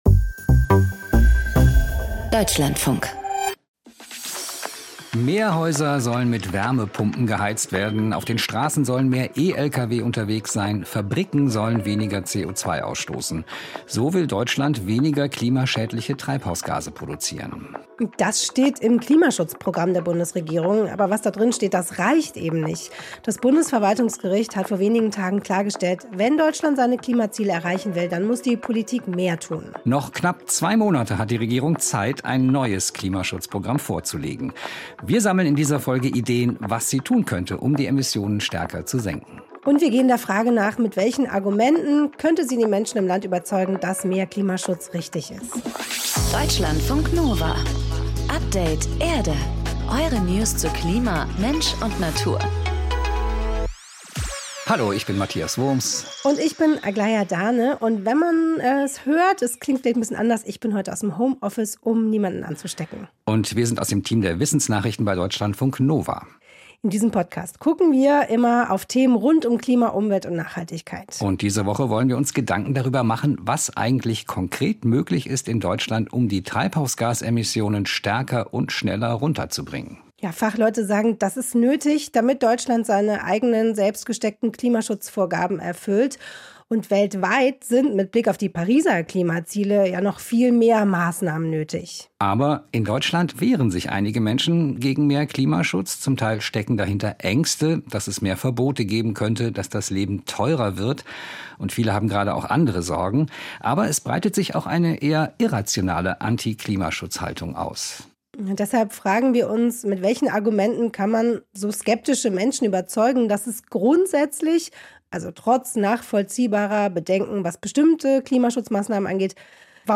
Deine Minute Natur: Froschkonzert in der Provence